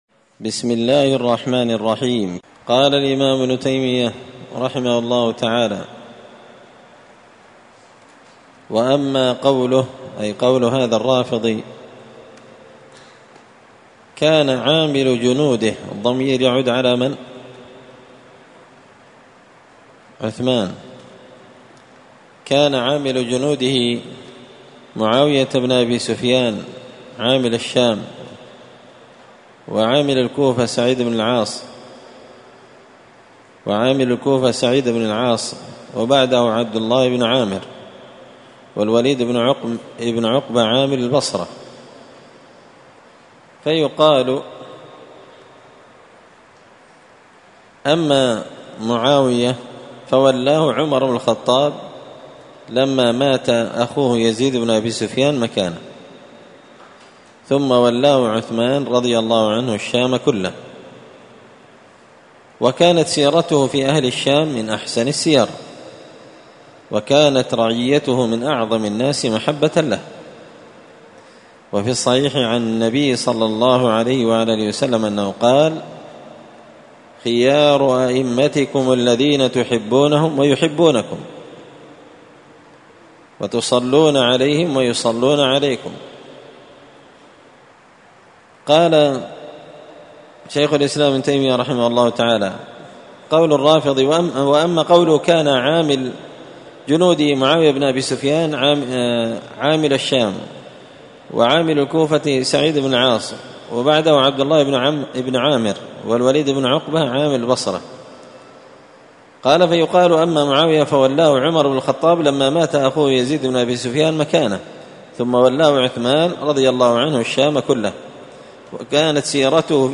الأربعاء 1 محرم 1445 هــــ | الدروس، دروس الردود، مختصر منهاج السنة النبوية لشيخ الإسلام ابن تيمية | شارك بتعليقك | 28 المشاهدات